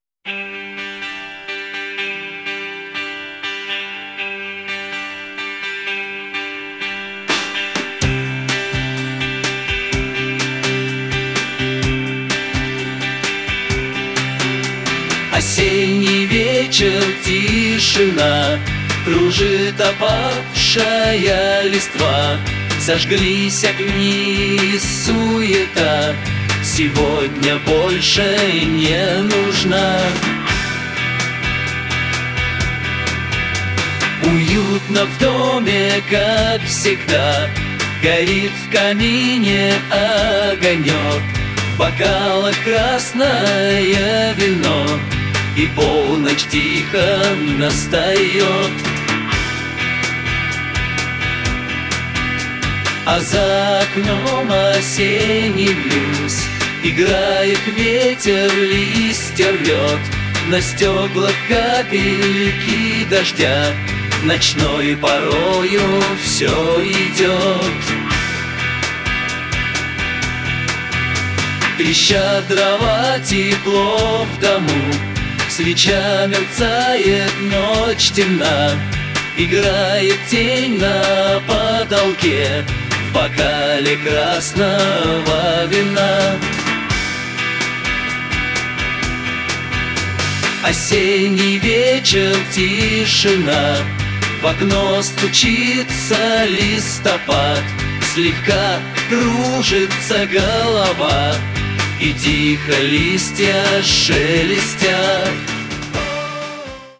Осенний_тихий_блюз
Osenniq_tihiq_bljyz.mp3